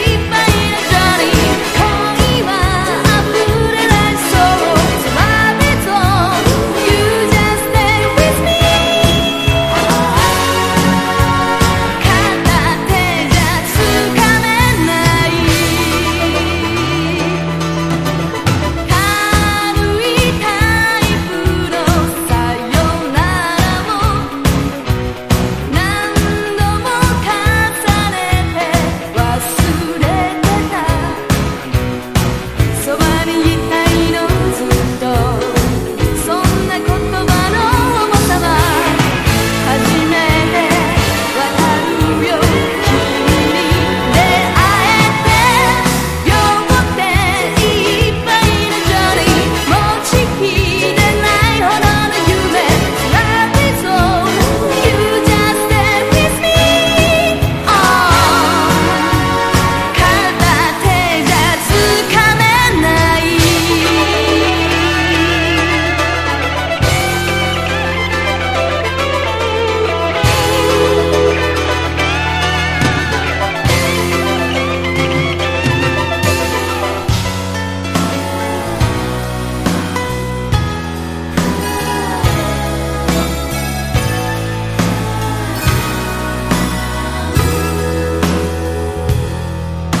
POP